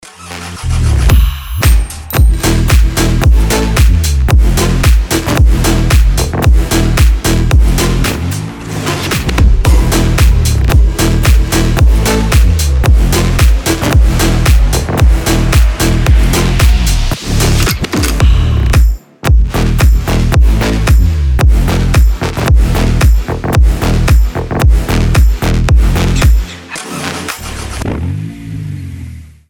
мощные басы
без слов
Bass House
качающие
взрывные
G-House
Разрывная клубная музыка